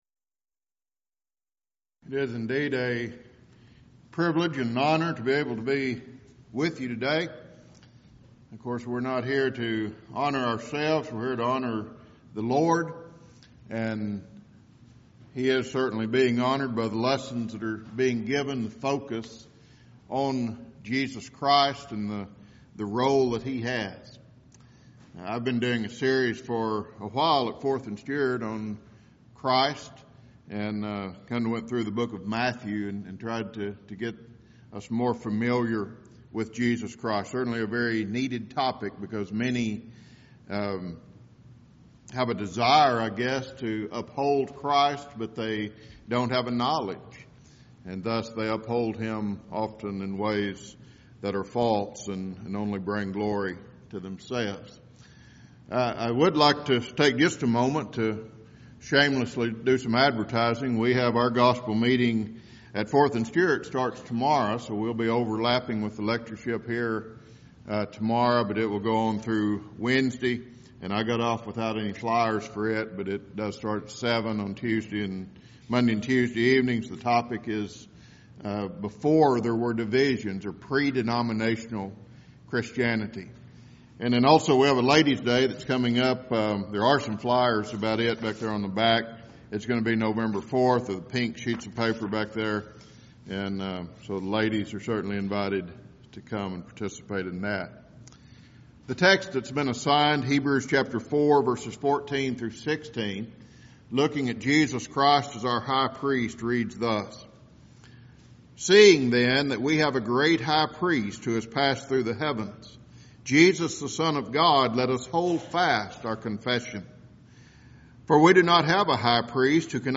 Alternate File Link File Details: Series: Back to the Bible Lectures Event: 8th Annual Back To The Bible Lectures Theme/Title: Do You Know The Christ?
lecture